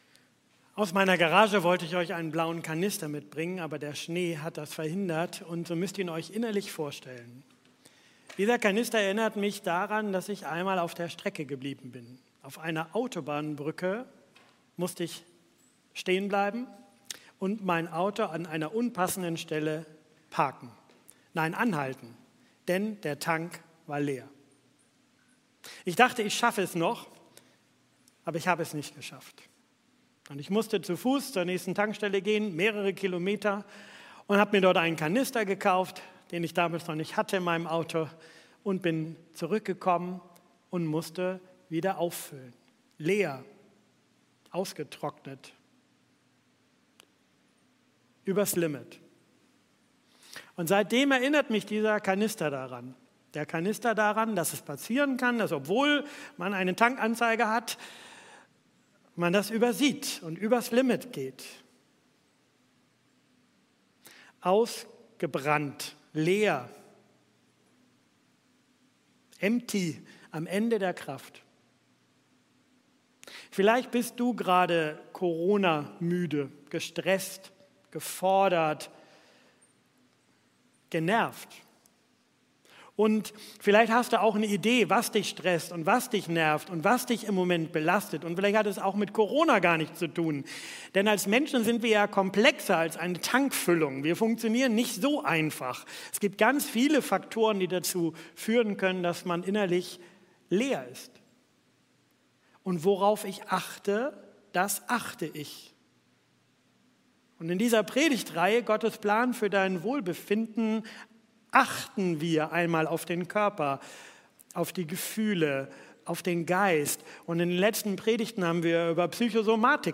Predigttext: 1.Könige 18, 41-46; 19, 1-16; Jakobus 5,17-18